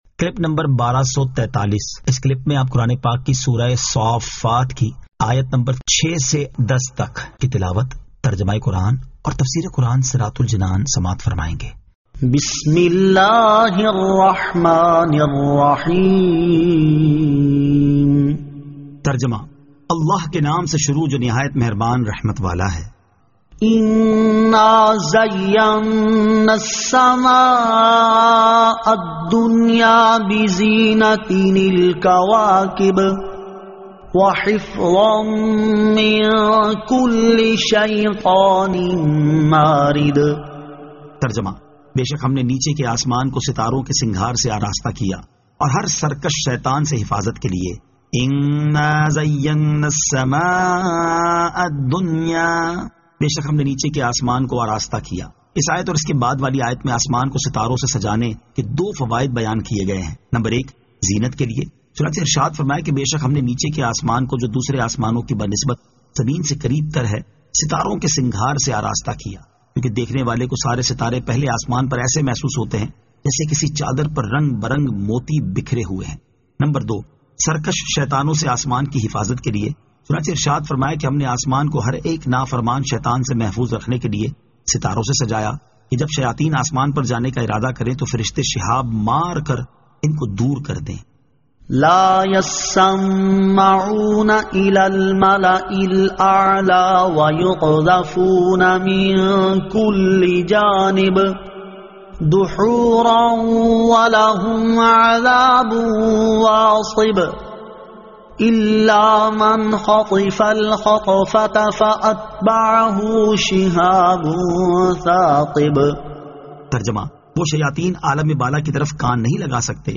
Surah As-Saaffat 06 To 10 Tilawat , Tarjama , Tafseer
2023 MP3 MP4 MP4 Share سُوَّرۃُ الصَّافَّات آیت 06 تا 10 تلاوت ، ترجمہ ، تفسیر ۔